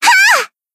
BA_V_Koharu_Swimsuit_Battle_Shout_2.ogg